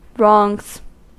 Ääntäminen
Ääntäminen US Haettu sana löytyi näillä lähdekielillä: englanti Käännöksiä ei löytynyt valitulle kohdekielelle. Wrongs on sanan wrong monikko.